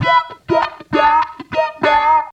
Index of /90_sSampleCDs/Zero-G - Total Drum Bass/Instruments - 2/track43 (Guitars)
07 Mocking Bird C#.wav